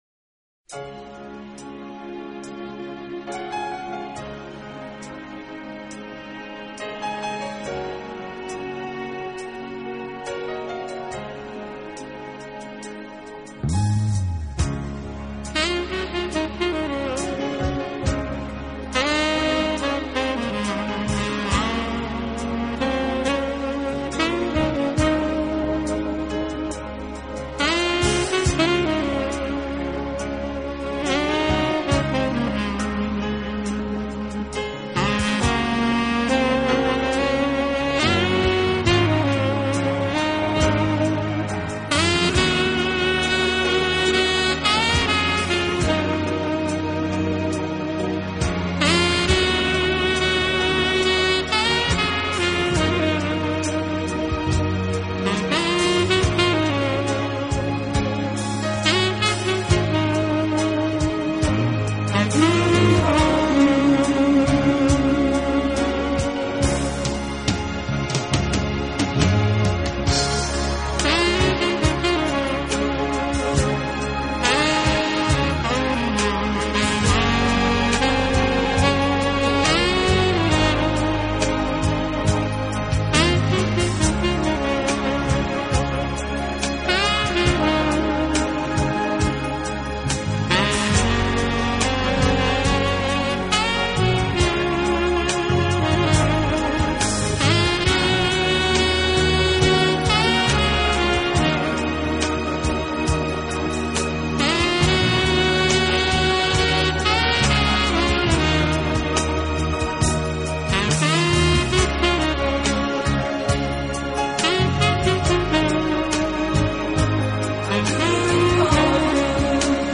轻快、柔和、优美，带有浓郁的爵士风味。